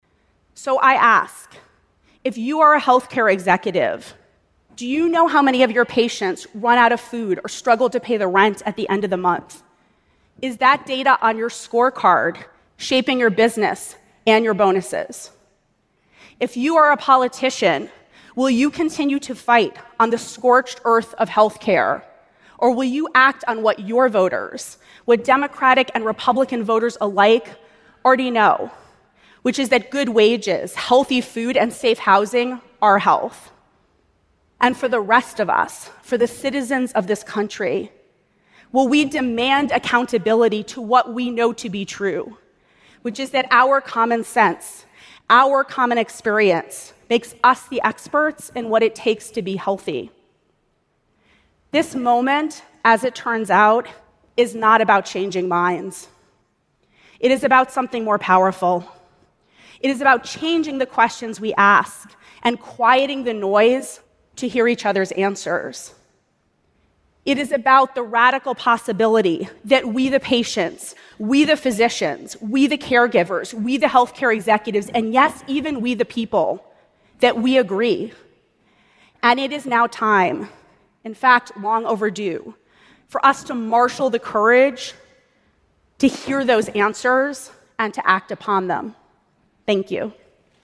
TED演讲:美国人在健康问题上的共识是什么(7) 听力文件下载—在线英语听力室